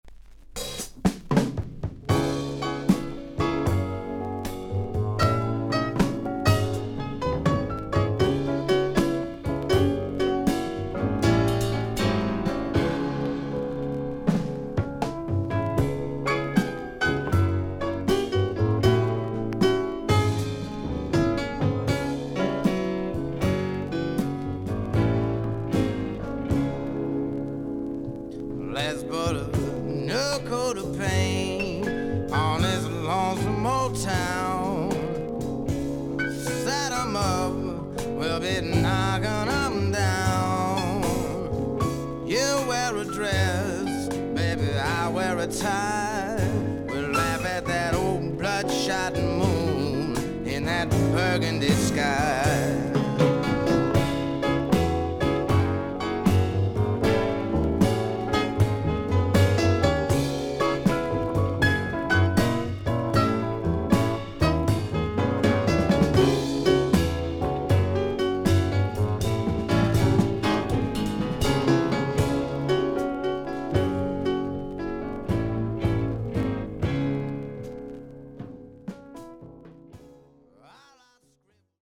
少々軽いパチノイズの箇所あり。少々サーフィス・ノイズあり。クリアな音です。
ジャズ色を強めた2ndアルバム。